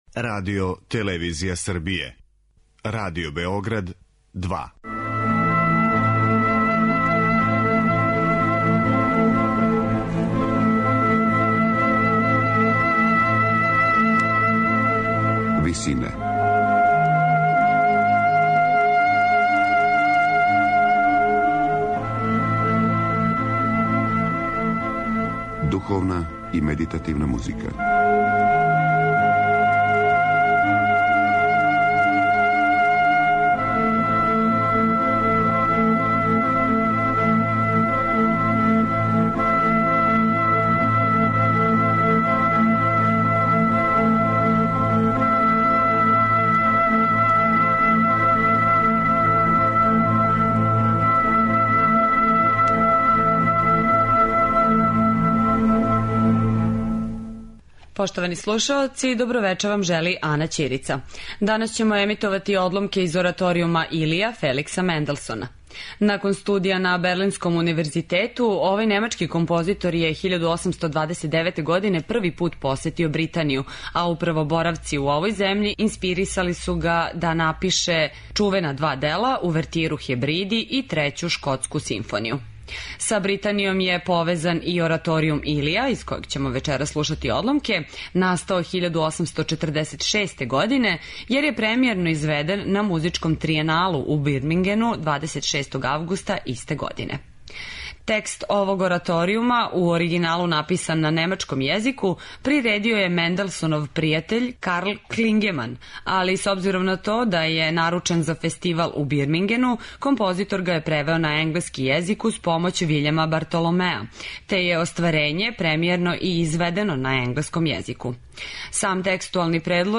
Oраторијум „Илија'
Иако је написан по узору на Бахове и Хендлове радове са тог подручја, ораторијум „Илија" кроз лиризам, оркестарски и хорски колорит проноси Менделсонову генијалност у оквиру ранoромантичарског стилског одређења.